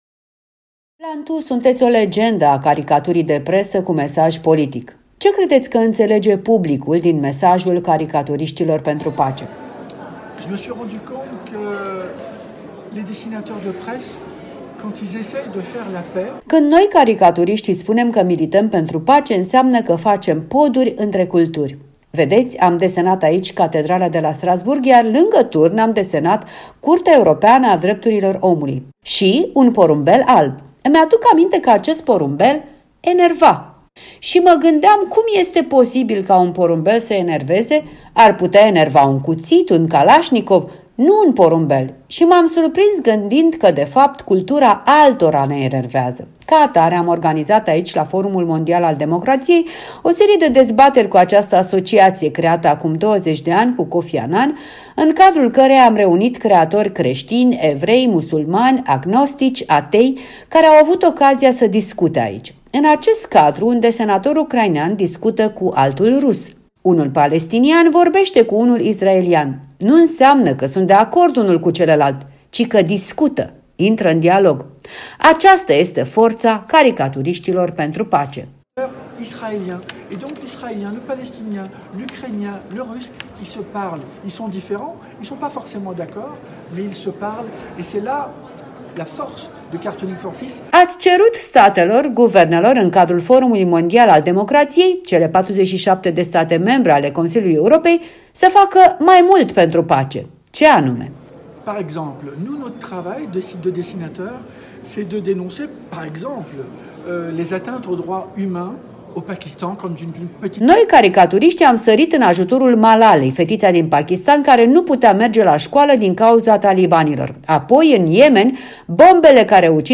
O discuție despre libertatea de expresie cu caricaturistul francez Plantu